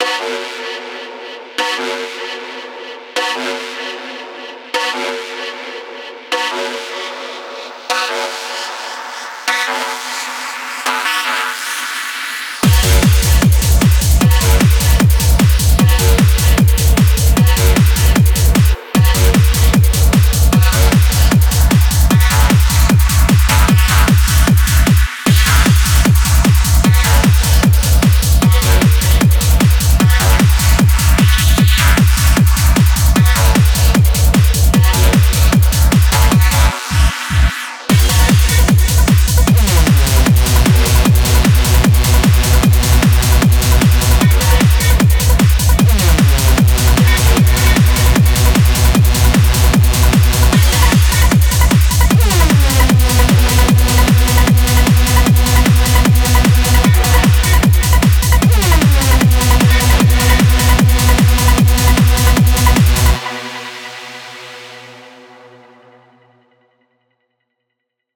schranz or smth demo. fast techno?